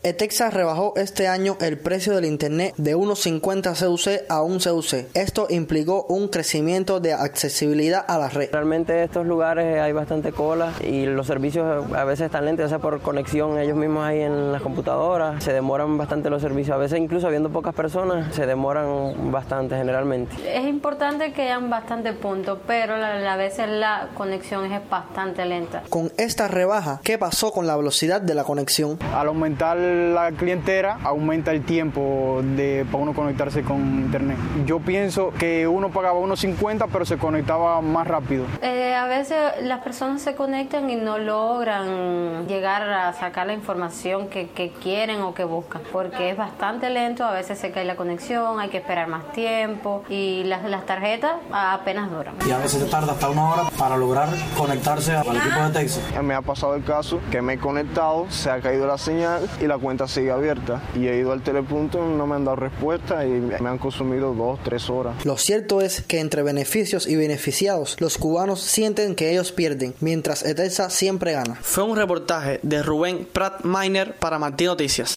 Cubanos reaccionan a la promesa de internet para móviles en 2018
Así reaccionaron cubanos desde la isla, al conocer la noticia.